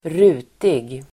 Ladda ner uttalet
Uttal: [²r'u:tig]